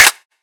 Index of /99Sounds Music Loops/Drum Oneshots/Twilight - Dance Drum Kit/Claps